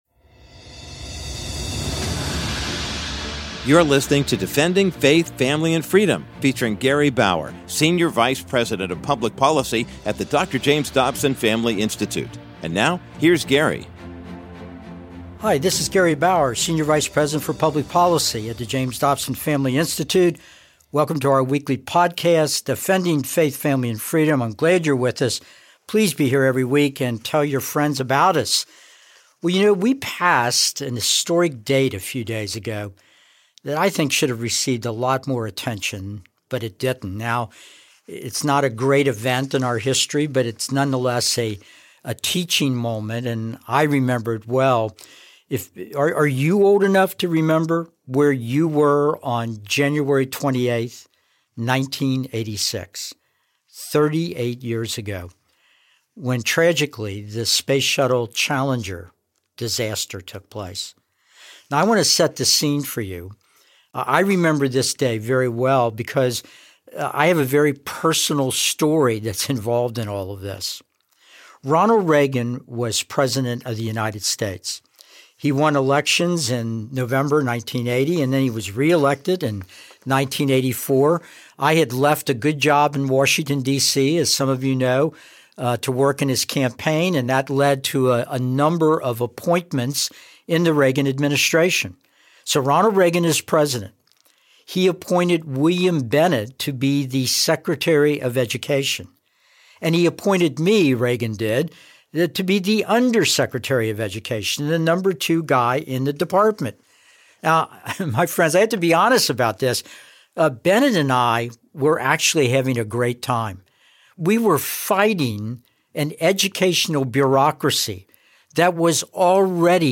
In this week's episode of Defending Faith, Family, and Freedom, Gary Bauer relives the lead up to and the catastrophic aftermath of the 25th NASA Space Shuttle flight of Challenger. Bauer plays audio from the moment of this tragic accident, where seven brave crew members perished. He also plays portions of the eloquent address to the nation by President Ronald Reagan.